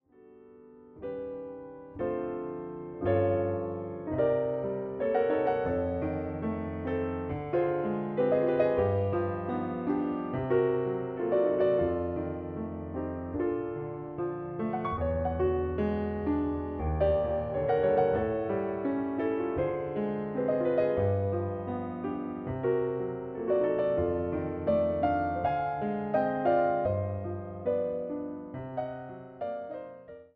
様々な情景、抒情を見せるピアノ・ソロの世界が広がるアルバムとなっています。